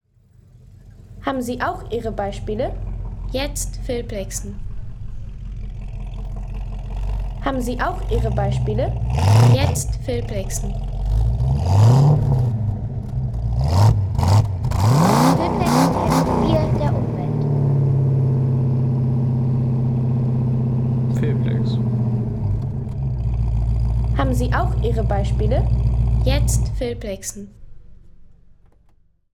Ford Mustang Fastback Coupé
Ford Mustang Fastback Coupé – Der Klang des Ur-Hengsts von 1964.